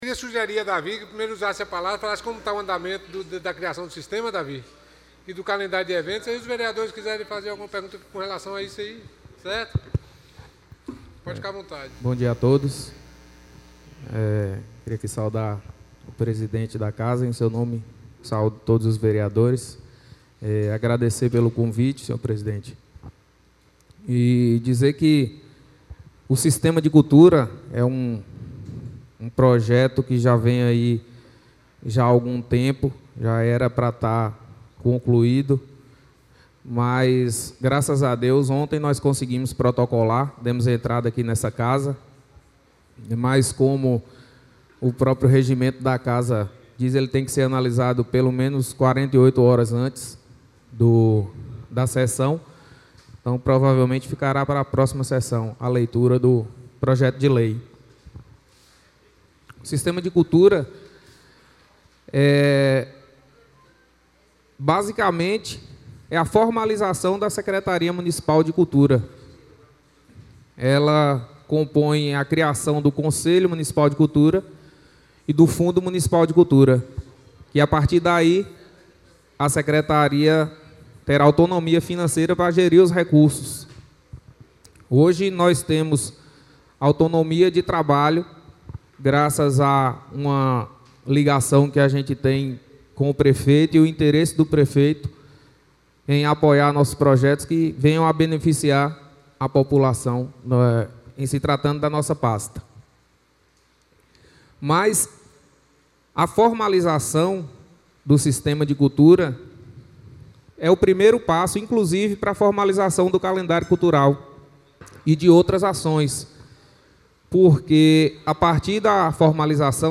O Secretário Municipal de Cultura DAVID DE SOUZA foi convocado para falar sobre assuntos Sobre o Sistema Municipal de Cultura e Calendário. O mesmo ocupou a mesa da presidência e falou sobre diversos assuntos relacionado à Cultura e Esporte no município.